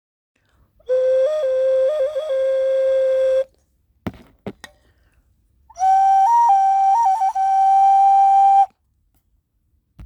特徴は、身の回りの自然素材を使い全て手作りであること、 人に優しい「倍音」をたっぷり含んだサウンドだということです。
マダガスカルの職人が作る掌サイズのひょうたんオカリナです。素朴な音色が癒されます。形状により音程はそれぞれ異なります。